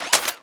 GUNMech_Reload_08_SFRMS_SCIWPNS.wav